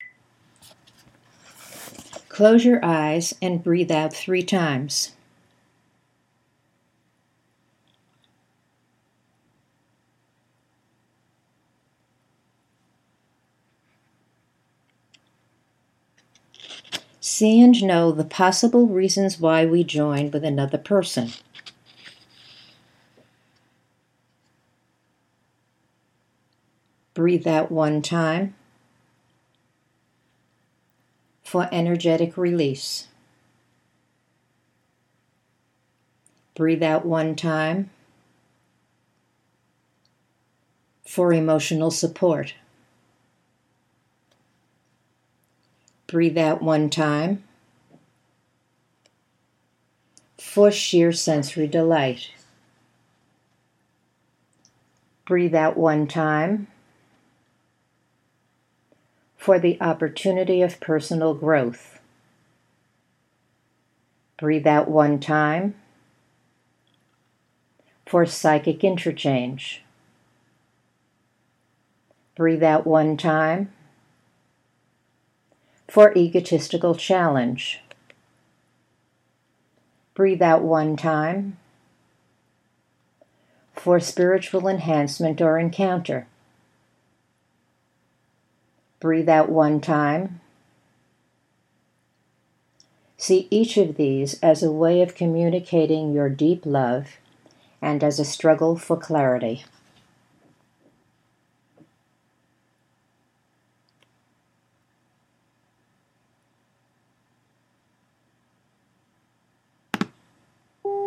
At the end of each exercise you’ll find a quiet space of 8 seconds to focus on your images.
The simple version: until you hear the beep, there may be another instruction.